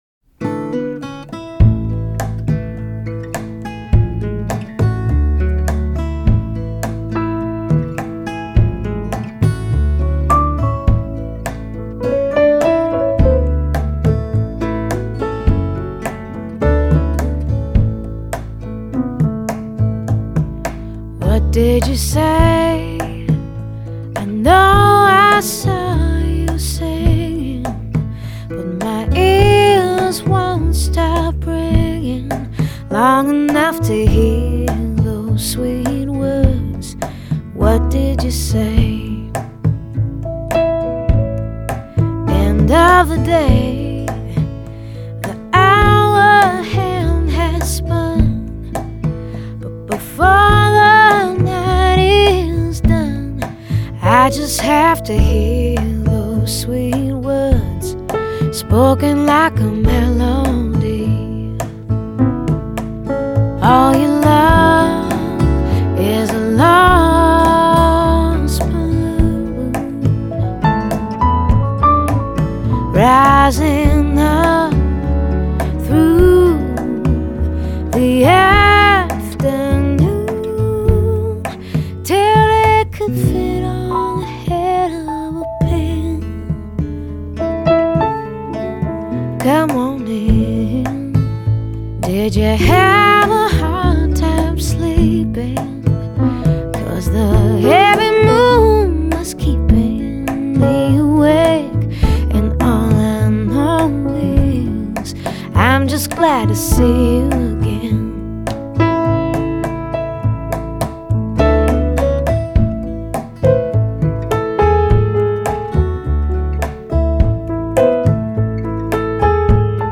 类型:爵士